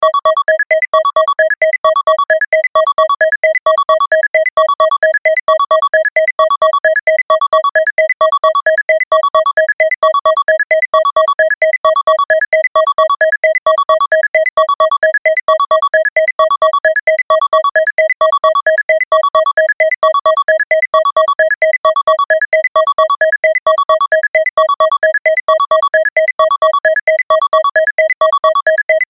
ピコピコとしたビープ音の目覚ましアラーム音。